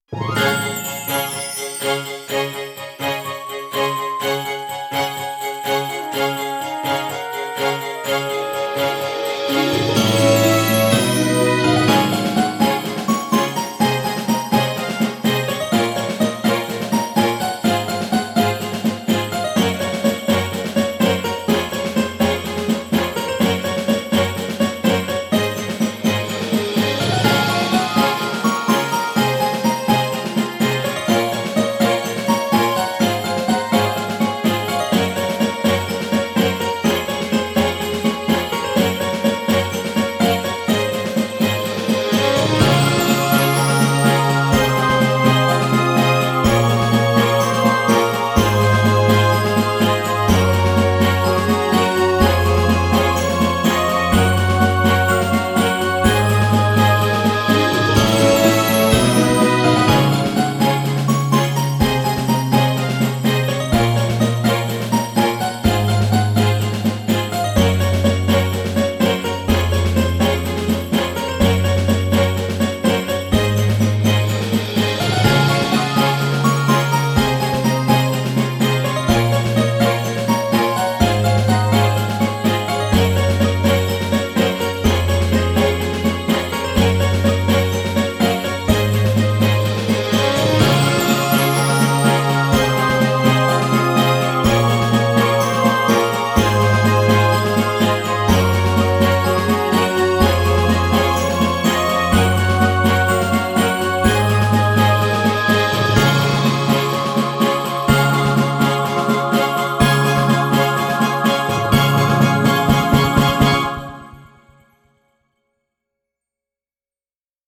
かわいいクリスマスBGMです！